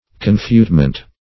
Confutement \Con*fute"ment\, n.